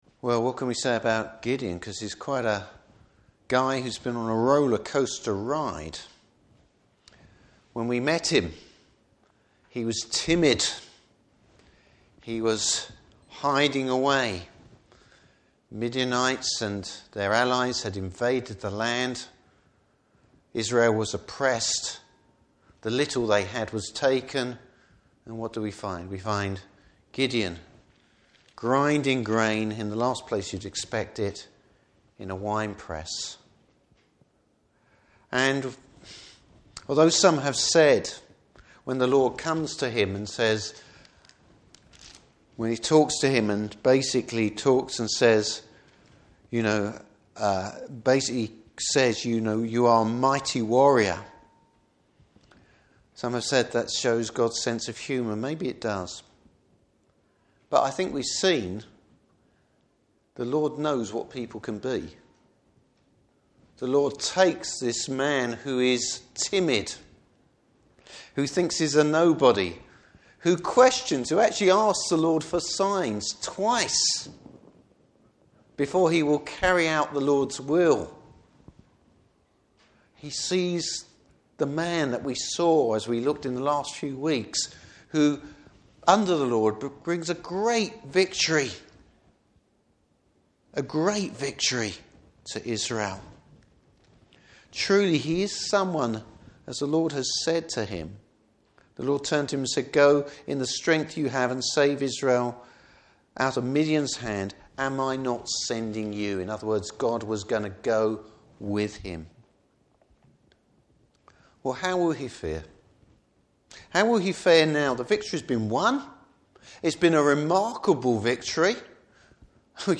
Service Type: Evening Service Bible Text: Judges 8:22-35.